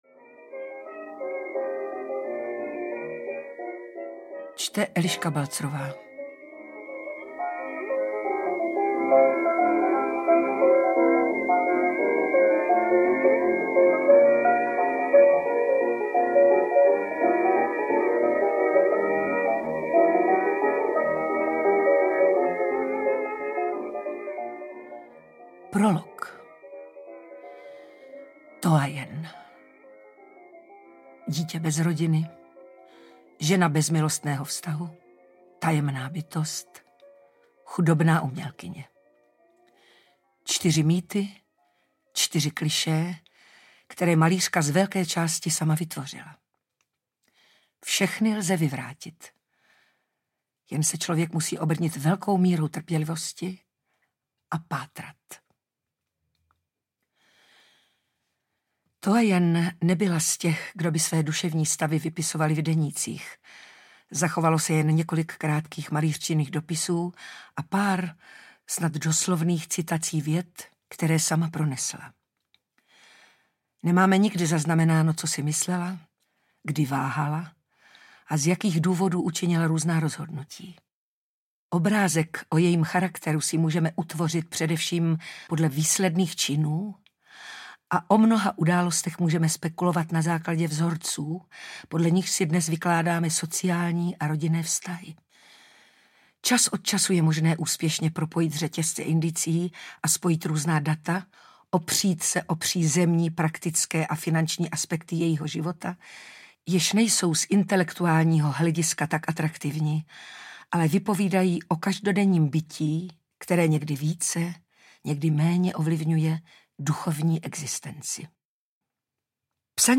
Toyen audiokniha
Ukázka z knihy
Eliška Balzerová čte audioknihu s nesmírným zaujetím a posluchači tak hodnověrně zpřítomní malířčin život i popisovaná výtvarná díla.
• InterpretEliška Balzerová